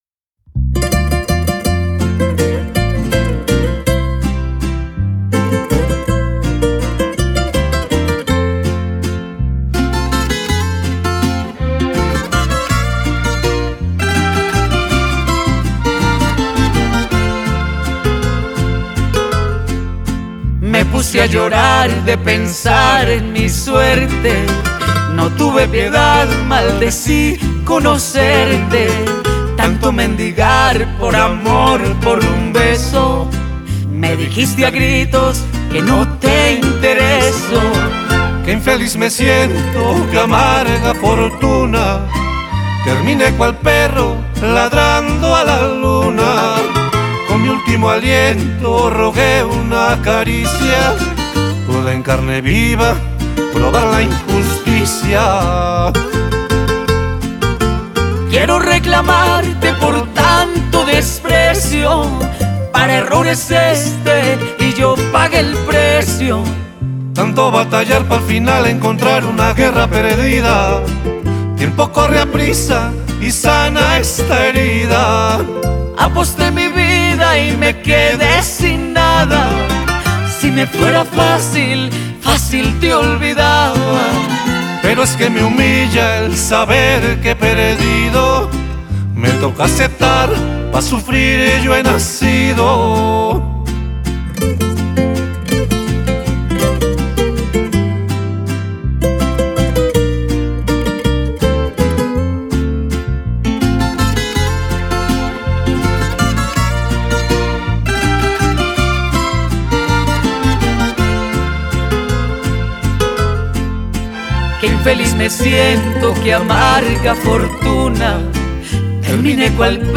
carranga